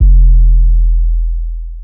808 (Simple).wav